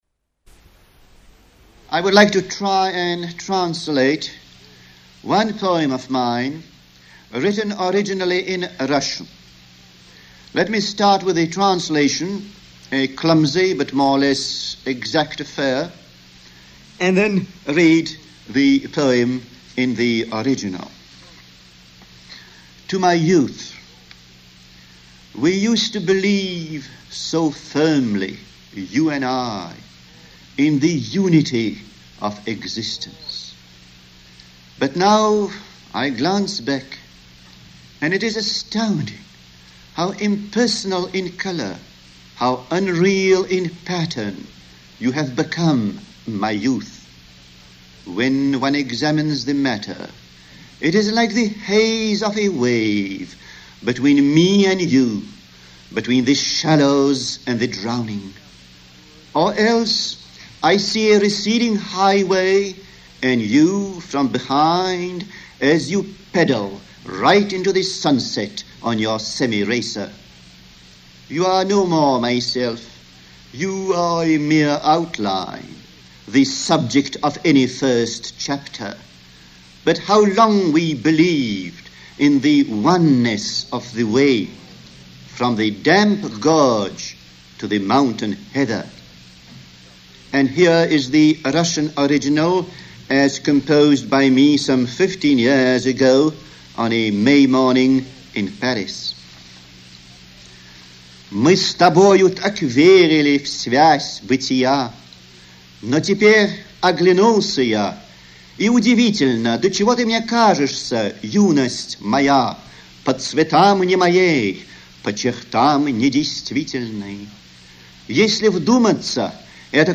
Декламация его редкая и замечательная!